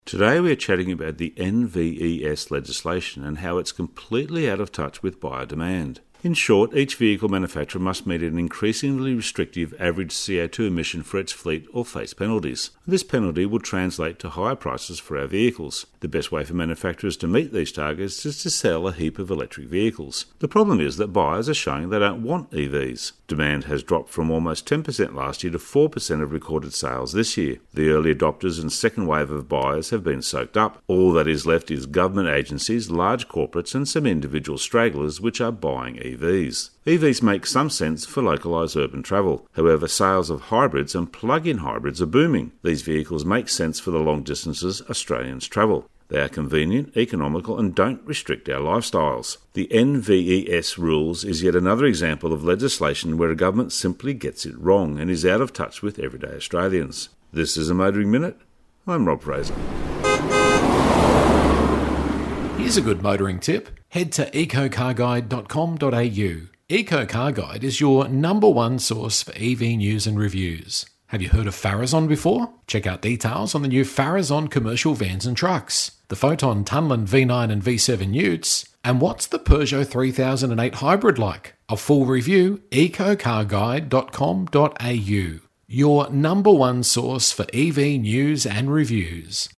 Motoring Minute is heard around Australia every day on over 100 radio channels.